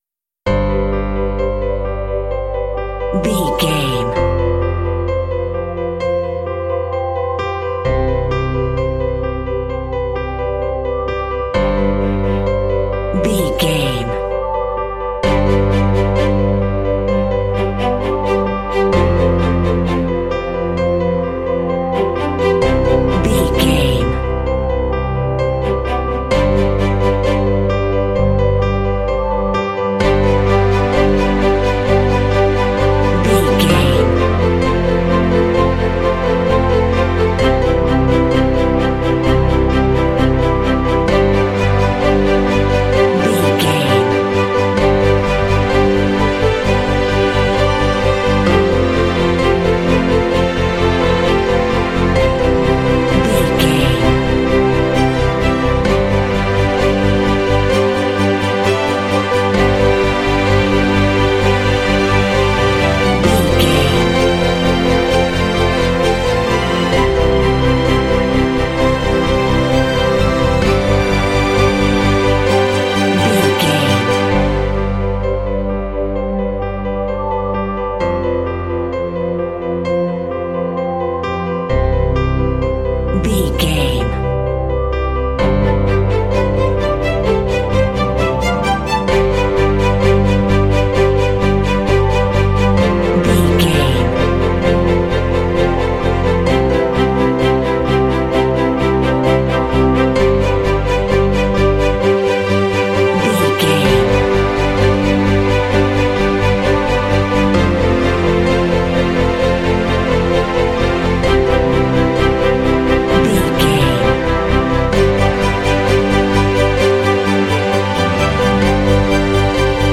Epic / Action
Mixolydian
intense
proud
inspirational
piano
strings
synthesiser
cinematic
symphonic rock